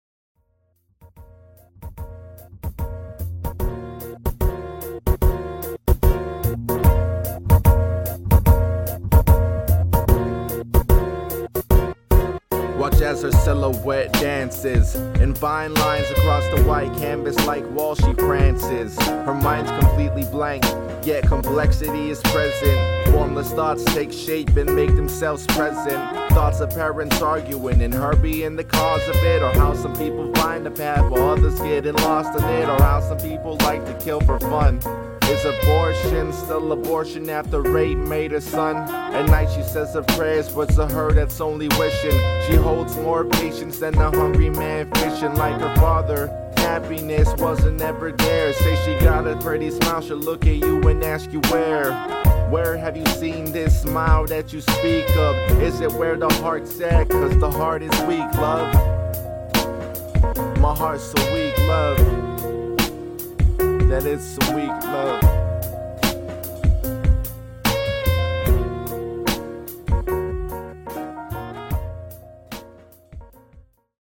Hey all! This is my first time in a long time getting back into recording at home.
Btw, I recorded this with a A-2020 USB Mic and it was over a one track instrumental.